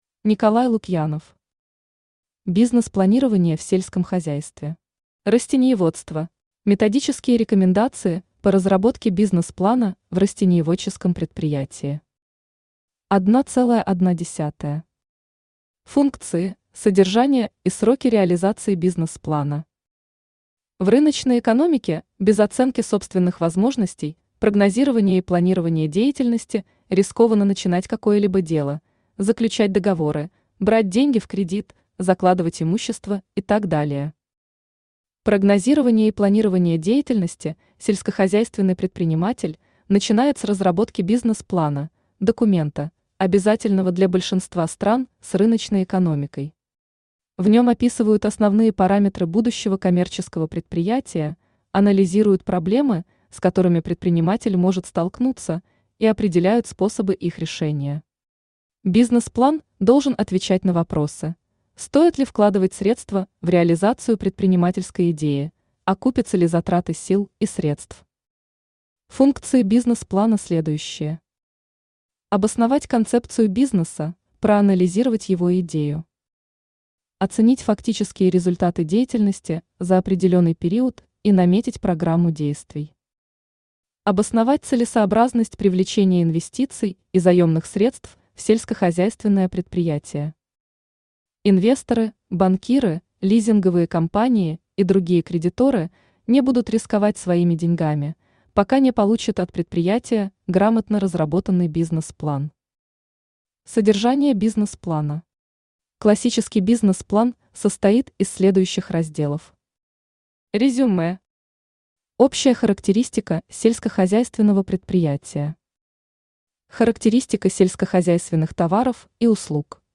Аудиокнига Бизнес-планирование в сельском хозяйстве. Растениеводство | Библиотека аудиокниг
Растениеводство Автор Николай Вячеславович Лукьянов Читает аудиокнигу Авточтец ЛитРес.